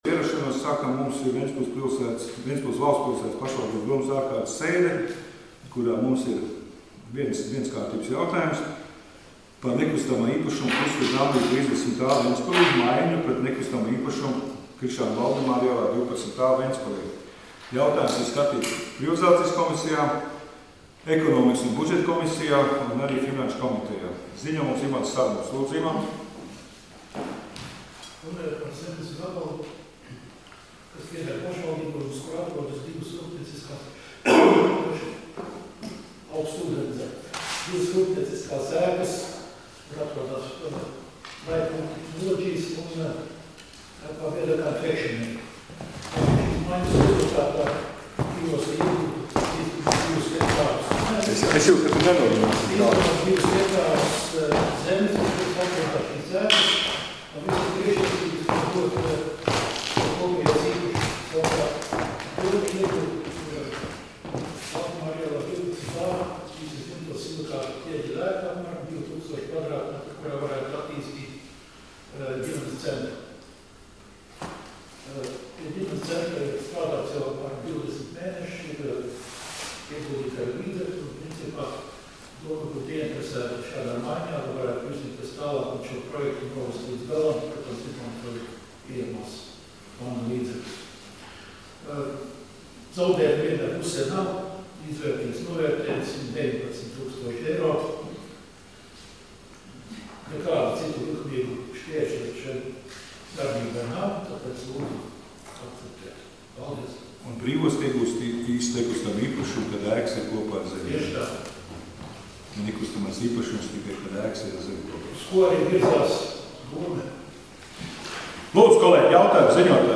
Domes sēdes 23.01.2020. audioieraksts